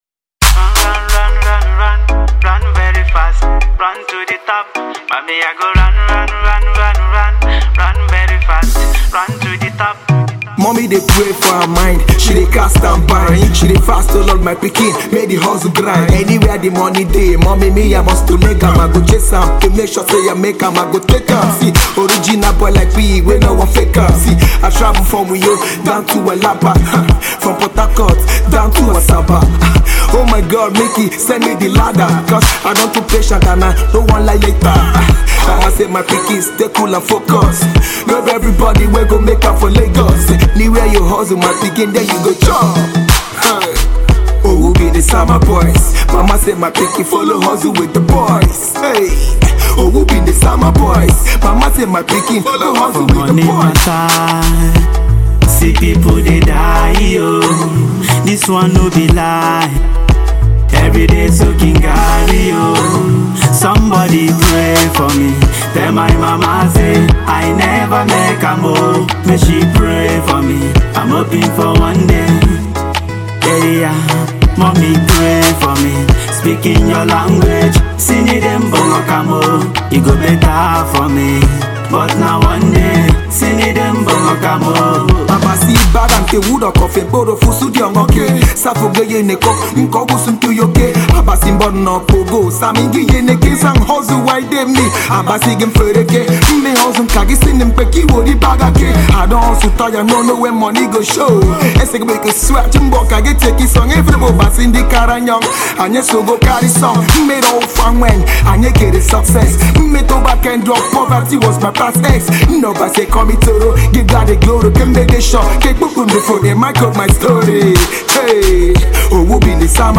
indigenous Ibibio Rap